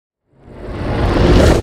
Minecraft Version Minecraft Version snapshot Latest Release | Latest Snapshot snapshot / assets / minecraft / sounds / mob / warden / sonic_charge3.ogg Compare With Compare With Latest Release | Latest Snapshot
sonic_charge3.ogg